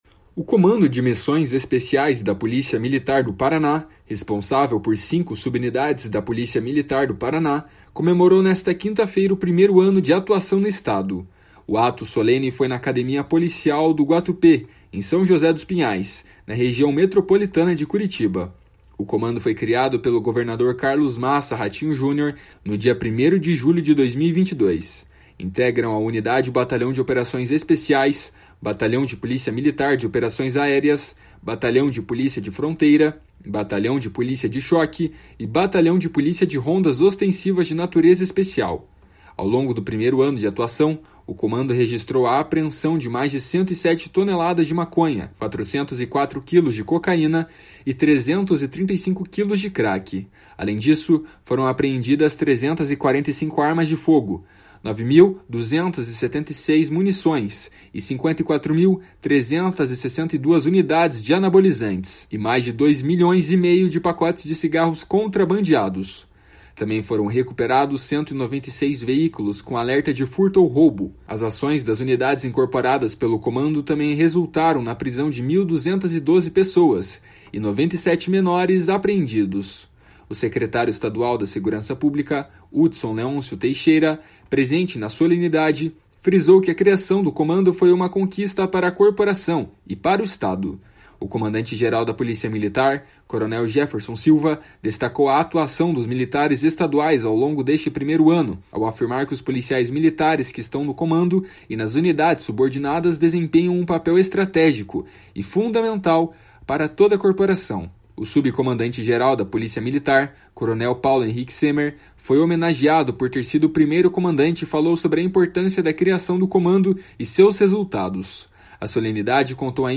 O Comando de Missões Especiais da Polícia Militar do Paraná, responsável por cinco subunidades da Polícia Militar do Paraná, comemorou nesta quinta-feira o primeiro ano de atuação no Estado. O ato solene foi na Academia Policial Militar do Guatupê, em São José dos Pinhais, na Região Metropolitana de Curitiba.